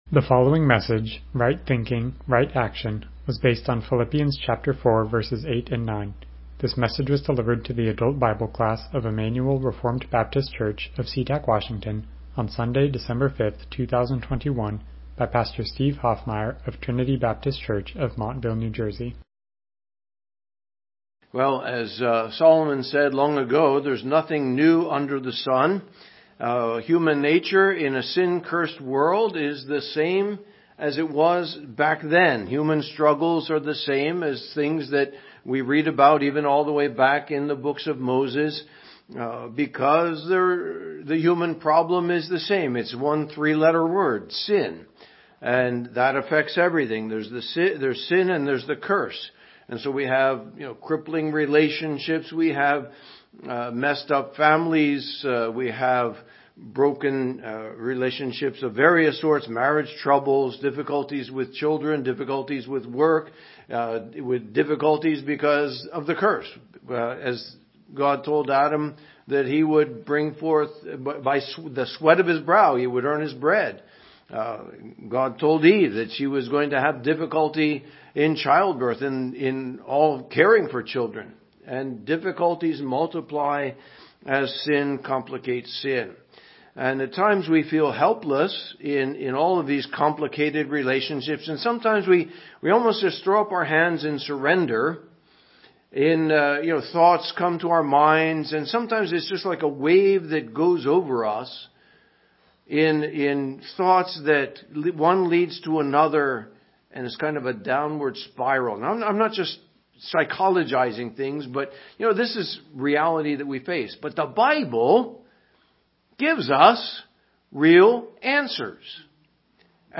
Passage: Philippians 4:8-9 Service Type: Sunday School « Where Is Your Treasure?